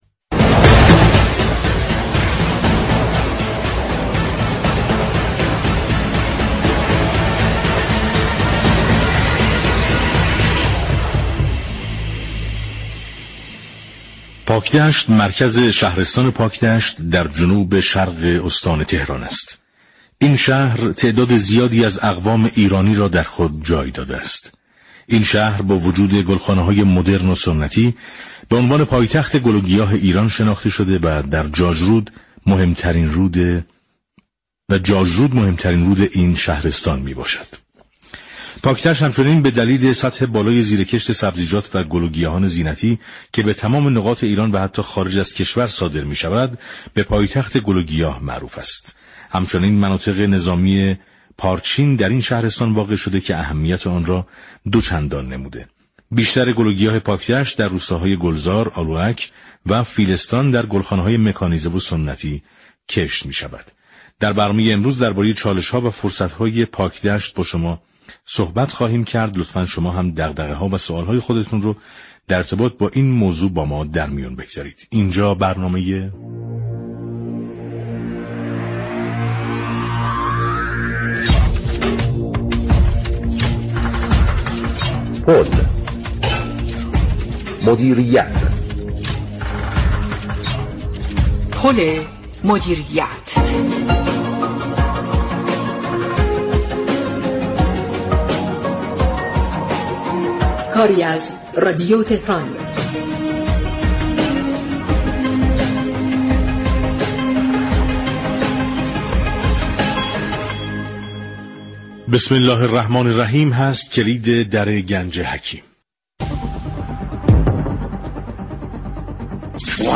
به گزارش پایگاه اطلاع رسانی رادیو تهران؛ حمیدرضا شیخ ویسی شهردار پاكدشت با حضور در استودیو پخش زنده رادیو تهران درباره اقدامات شهرداری پاكدشت و فرصت ها و چالش های این شهر با برنامه پل مدیریت 3 اسفند گفت و گو كرد.